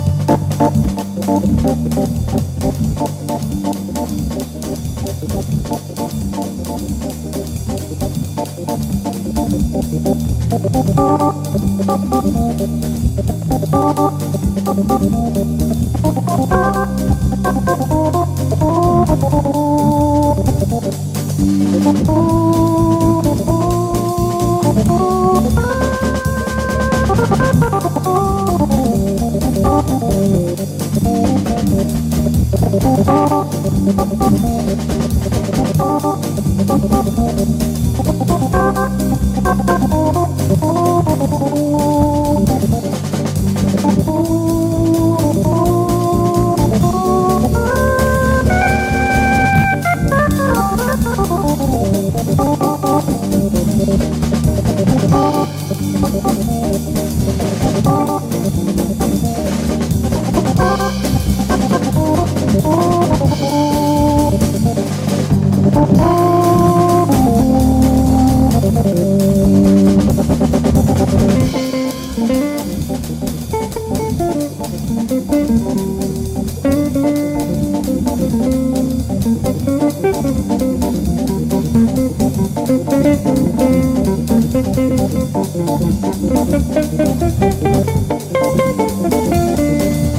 SOUL / SOUL / 60'S / FREE SOUL